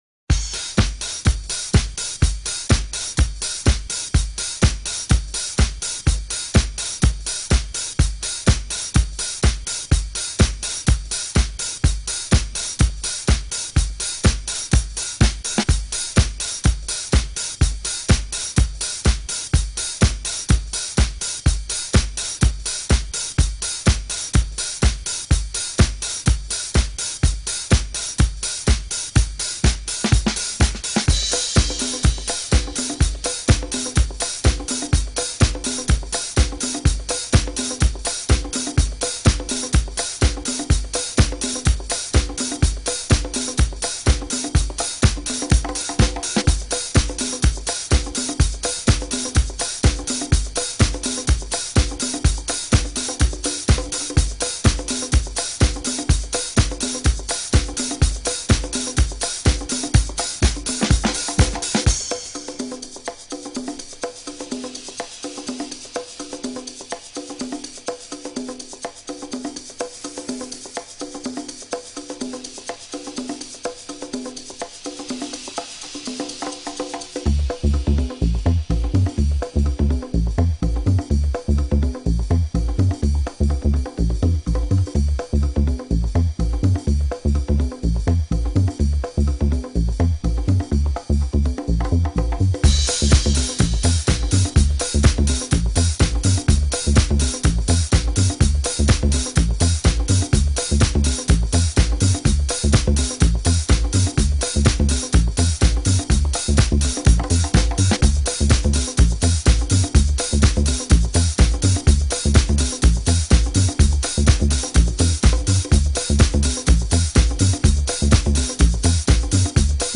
Laser quest & Xylophone <3